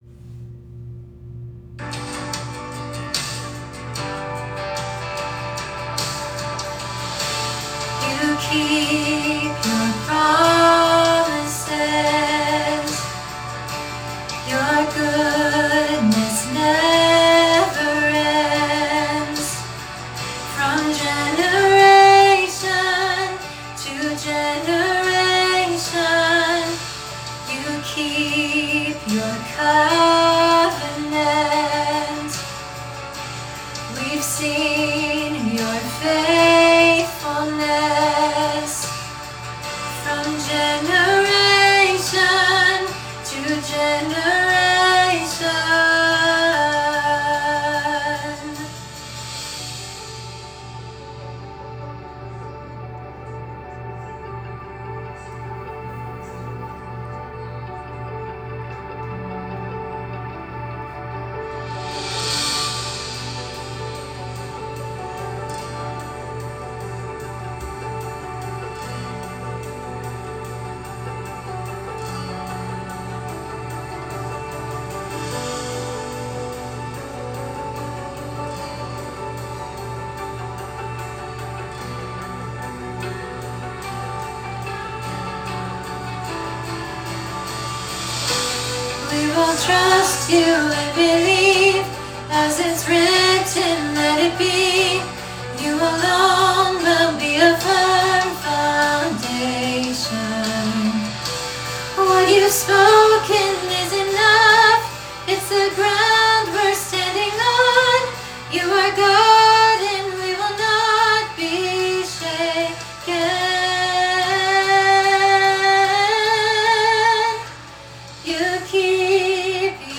Lyric Sheet, Sheet Music & Vocal Rehearsal Tracks
The audio quality may be a bit raw but it will be sufficient for you to practice on your own.
Female Alto Track